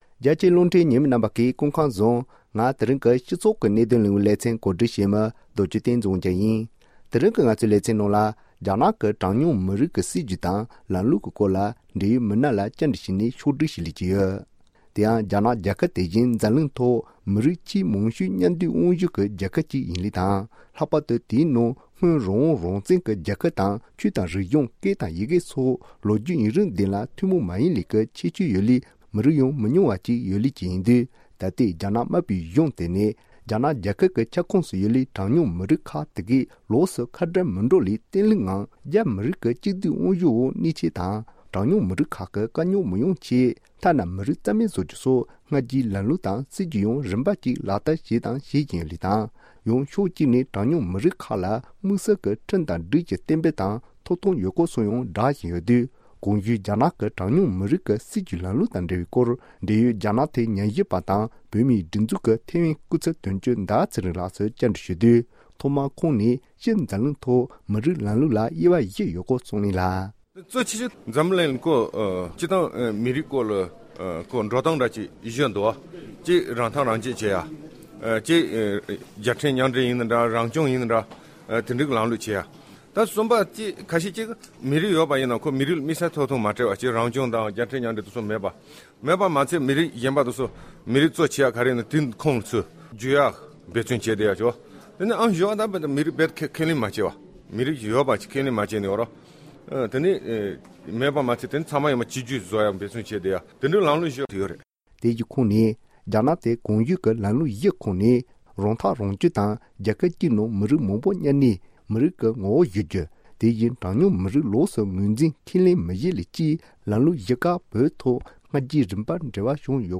སྒྲ་ལྡན་གསར་འགྱུར། སྒྲ་ཕབ་ལེན།
༄༅།    །རྒྱ་ནག་ནས་གྲངས་ཉུང་མི་རིགས་ཐོག་བཟུང་བའི་སྲིད་ཇུས་སོགས་ཀྱི་སྐོར་འབྲེལ་ཡོད་མི་སྣ་ལ་བཅར་འདྲི་བྱས་ཏེ་ཕྱོགས་བསྒྲིགས་ཞུས་པ་ཞིག་ཡོད།